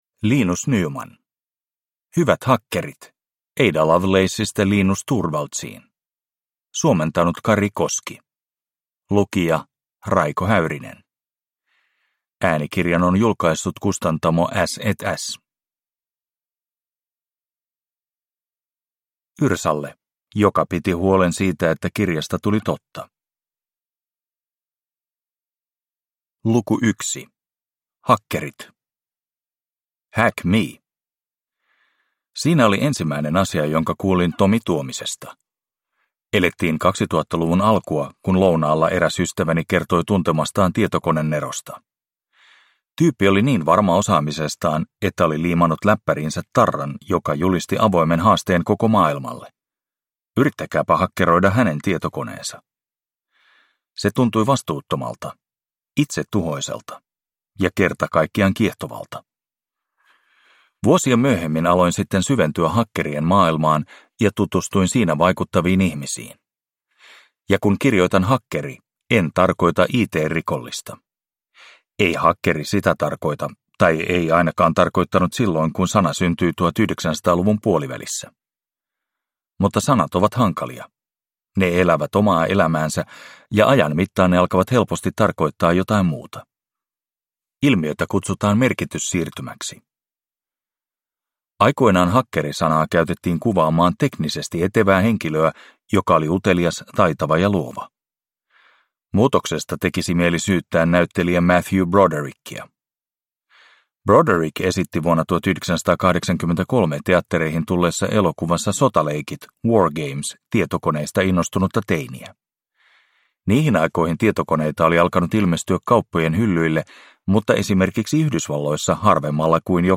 Hyvät hakkerit – Ljudbok